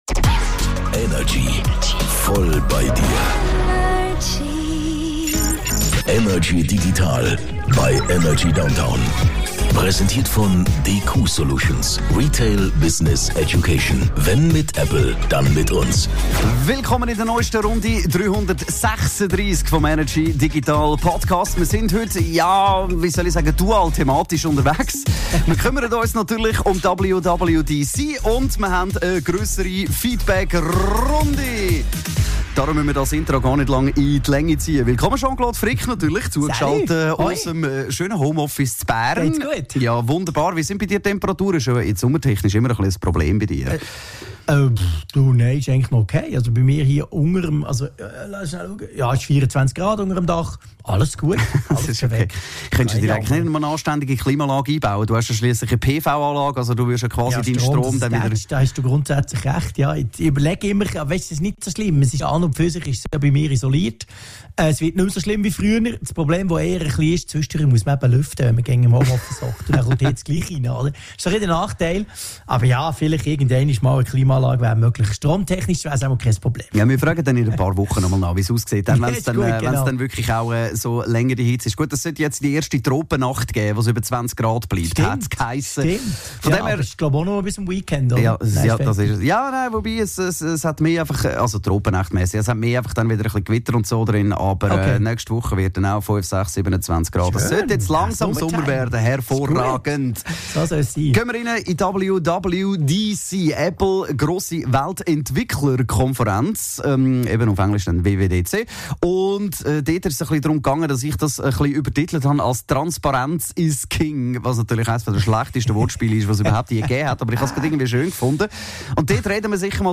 im Energy Studio
aus dem HomeOffice über die digitalen Themen der Woche